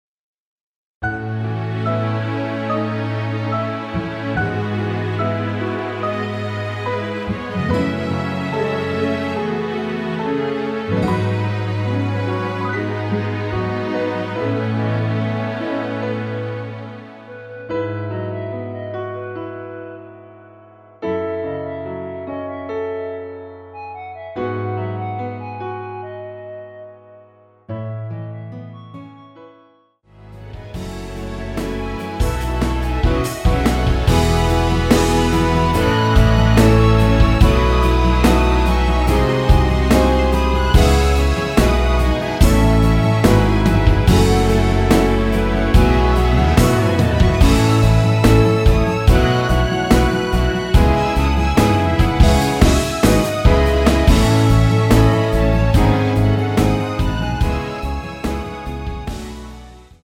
원키에서(+2)올린 대부분의 여성분이 부르실수 있는키로 제작 되었습니다.
(여자키)멜로디 포함된 MR 입니다.(미리듣기 참조)
앞부분30초, 뒷부분30초씩 편집해서 올려 드리고 있습니다.
중간에 음이 끈어지고 다시 나오는 이유는